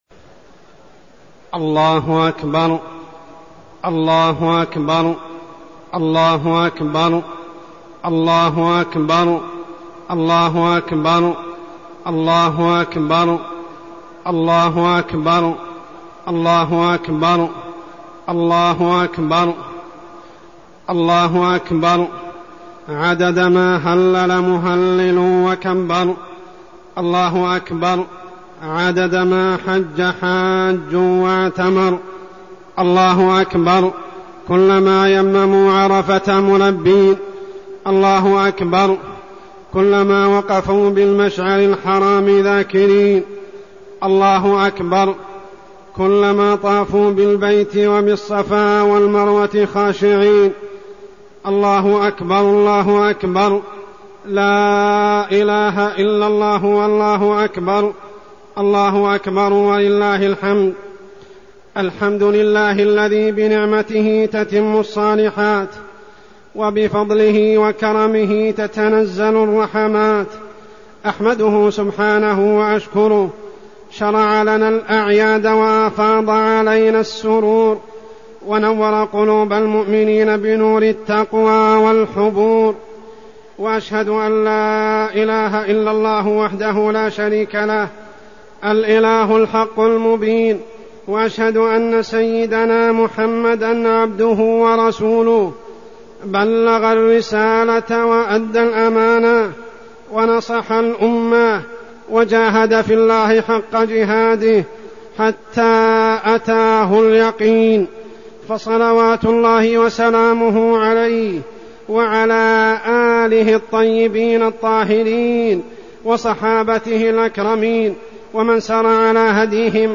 خطبة عيد الأضحى-العبادة لله
تاريخ النشر ١٠ ذو الحجة ١٤١٧ هـ المكان: المسجد الحرام الشيخ: عمر السبيل عمر السبيل خطبة عيد الأضحى-العبادة لله